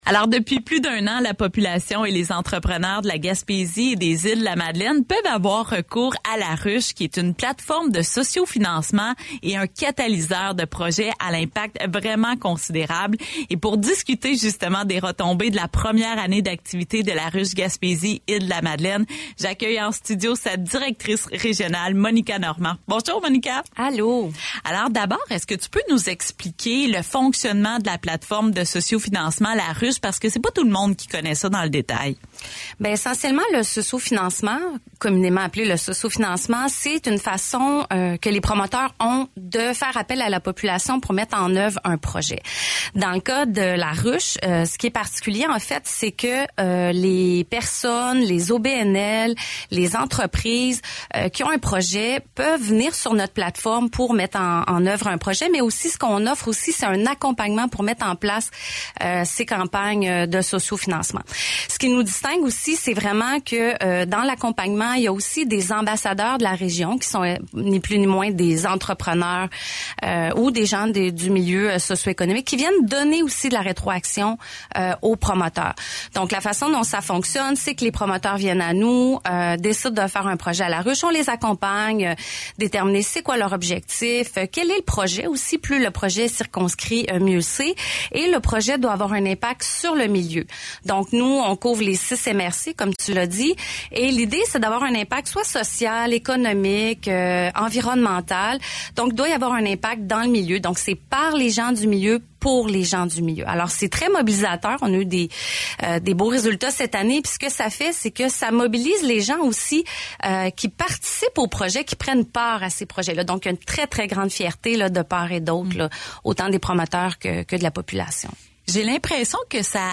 a reçu en studio